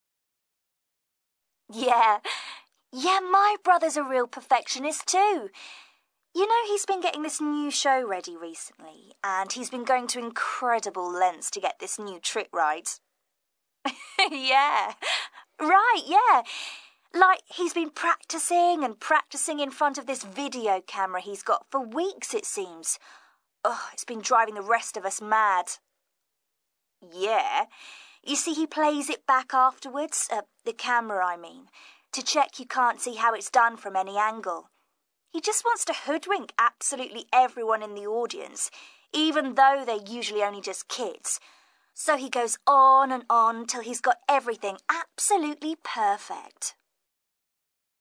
ACTIVITY 60: You will hear five short extracts in which five people are talking about a member of their family who they admire.